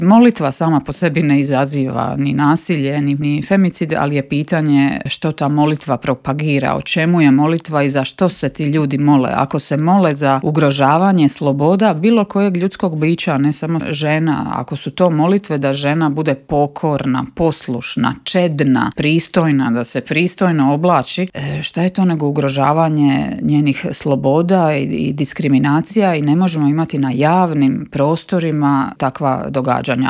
ZAGREB - U Intervjuu Media servisa ugostili smo saborsku zastupnicu i splitsku gradsku vijećnicu Centra Marijanu Puljak.